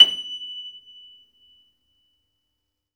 53d-pno24-F5.aif